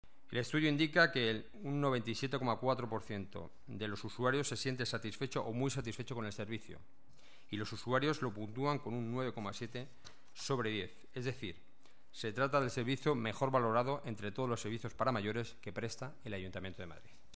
Nueva ventana:Enrique Núñez, Delegado de Seguridad y Movilidad y portavoz del gobierno municipal